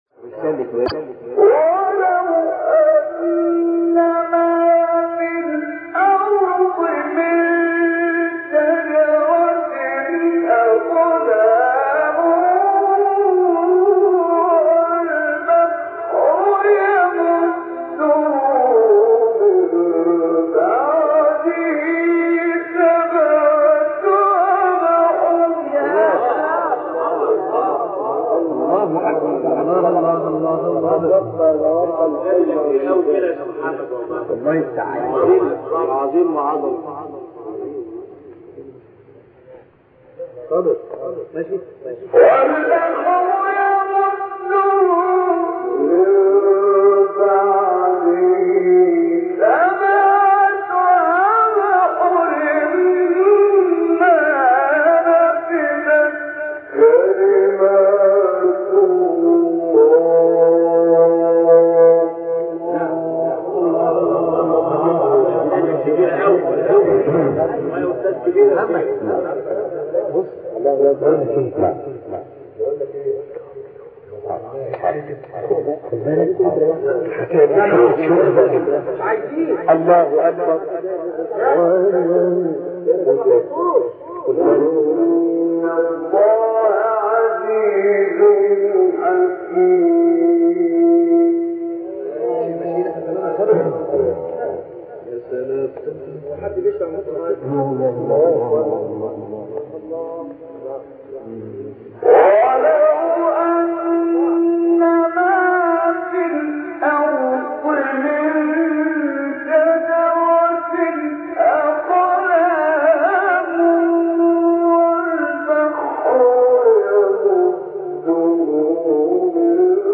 سوره : لقمان آیه: 27-28 استاد : محمد عمران مقام : بیات قبلی بعدی